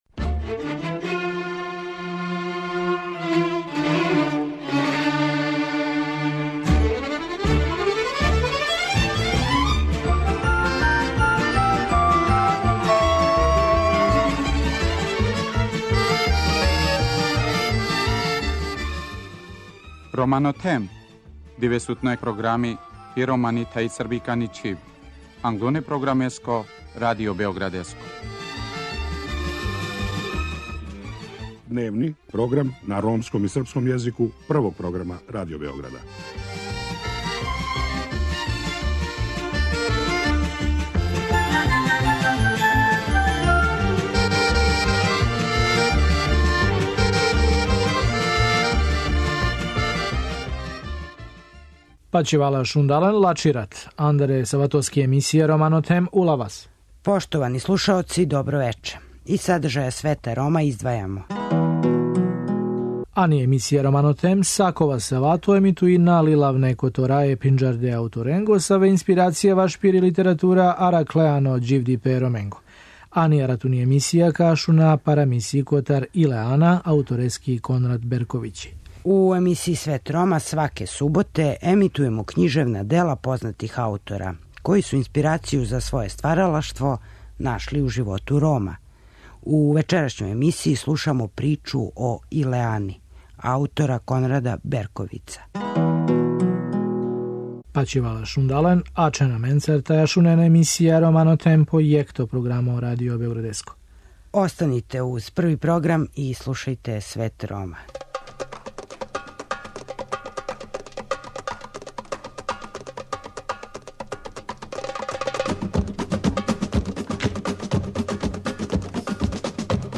У вечерашњој емисији чућете наставак приче о Илеани, аутора Конрада Берковица.